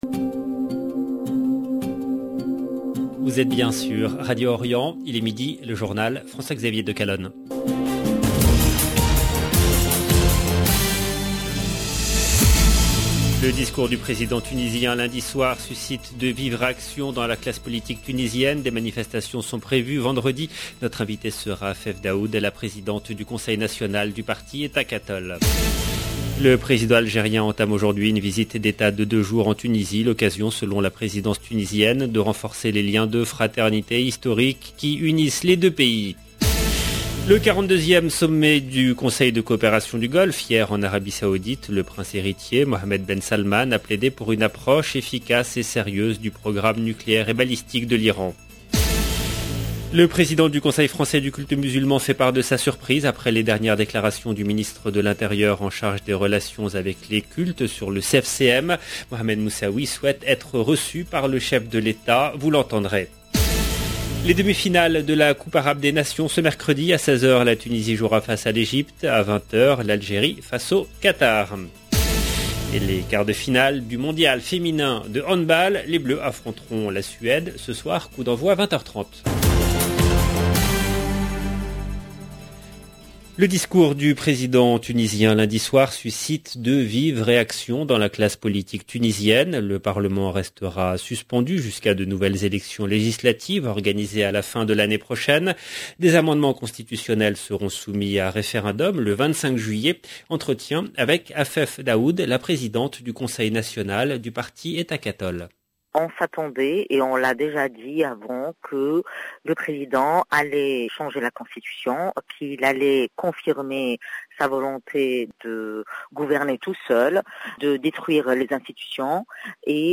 LE JOURNAL EN LANGUE FRANCAISE DU 15/12/21